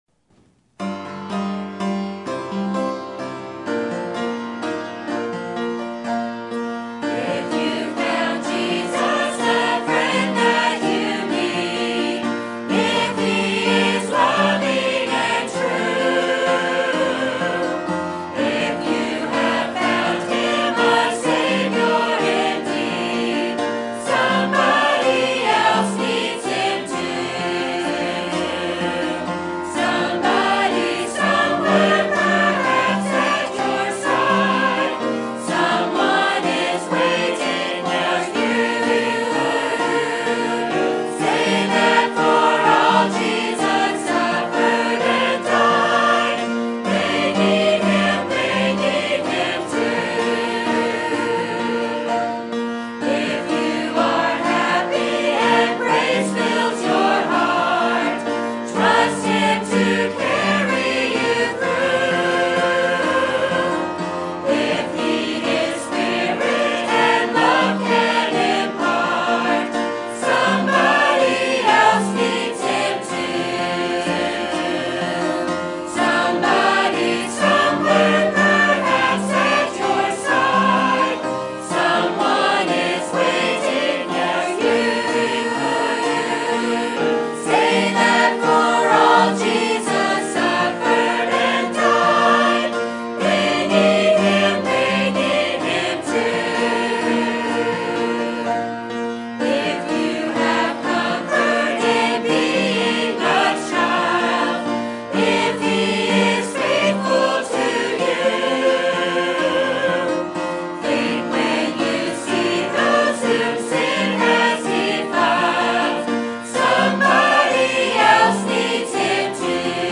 Sermon Topic: Missions Conference Sermon Type: Special Sermon Audio: Sermon download: Download (29.57 MB) Sermon Tags: 1 Kings Faith Missions Giving